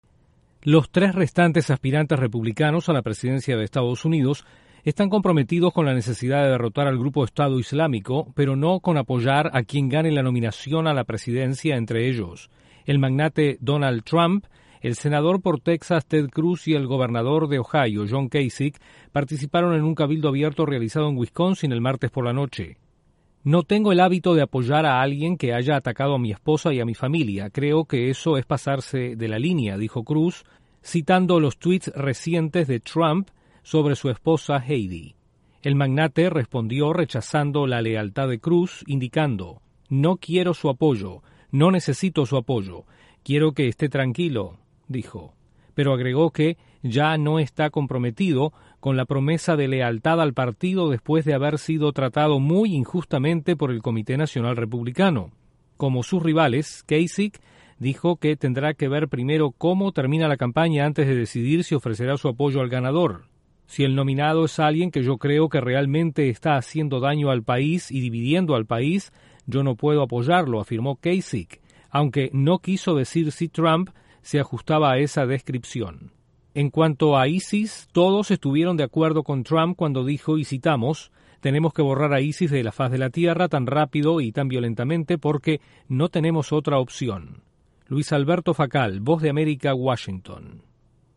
Los candidatos republicanos a la Casa Blanca reniegan de la promesa de apoyar al nominado presidencial. Desde la Voz de América en Washington informa